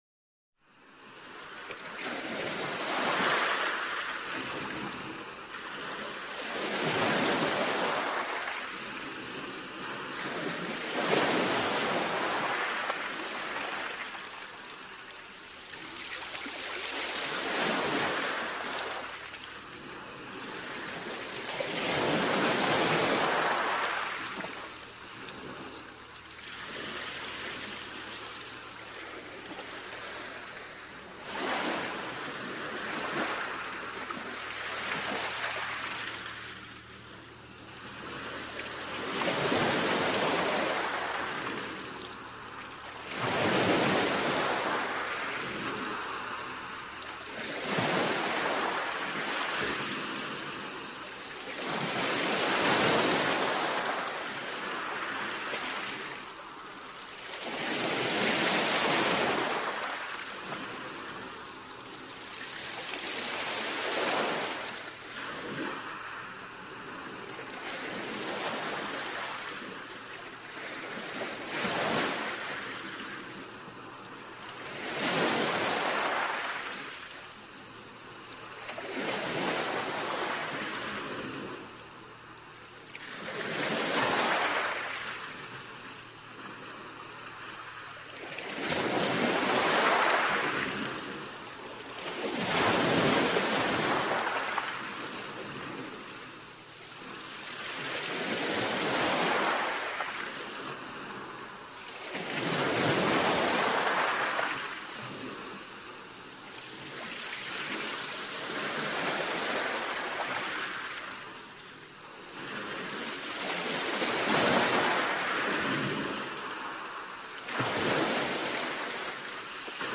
Звуки моря, волн
Шум средиземного моря